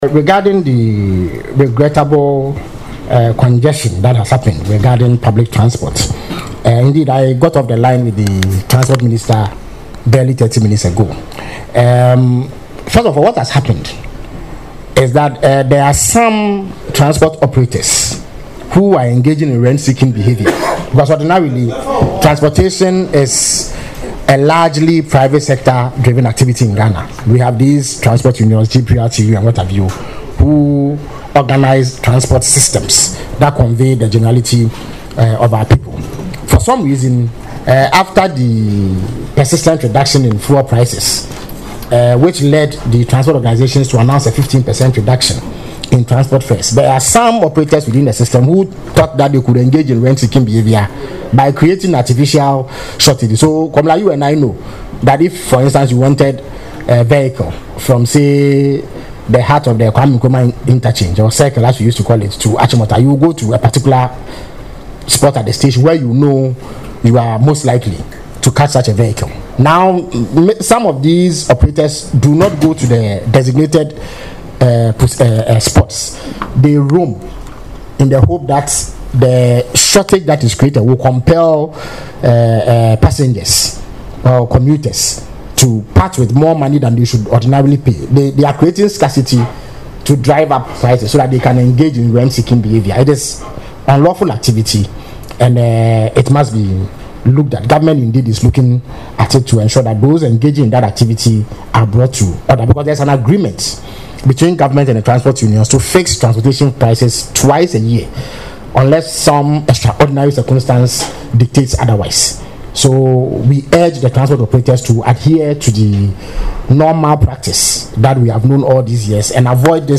Speaking during the Government Accountability Series on Wednesday, January 14, Mr. Kwakye Ofosu explained that some transport operators are deliberately engaging in rent-seeking behaviourby creating artificial shortages.
Listen to Felix Kwakye Ofosu explain the causes of the congestion in the audio below: